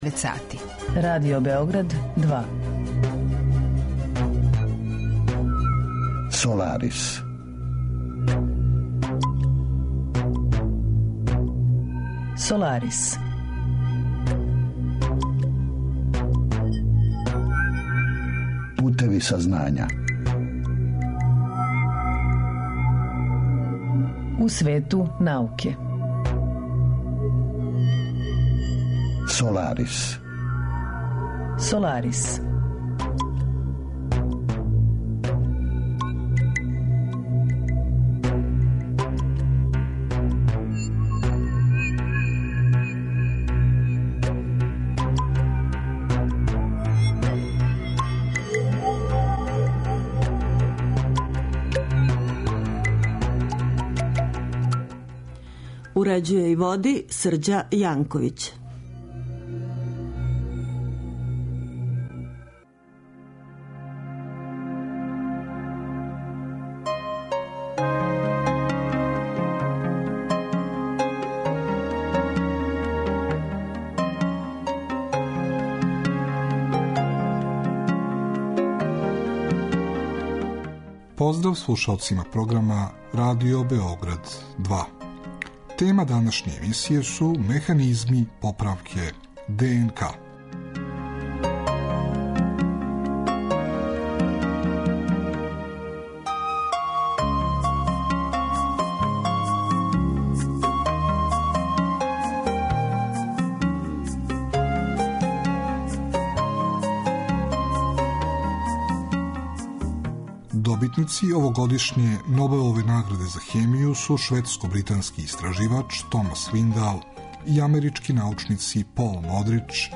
У краткој причи из историје науке подсетићемо се на први пронађени фосил аустралопитекуса - дете из Таунга, чију је лобању 1924. године пронашао аустралијски палеоантрополог Рејмонд Дарт. Емисија садржи и избор научних вести објављених у светским медијима од претходне среде, као и редовну рубрику "Ви сте то тражили", посвећену одговорима на питања слушалаца.